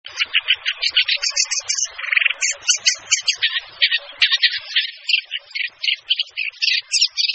En cliquant ici vous entendrez le chant de la Pie grièche à poitrine rose.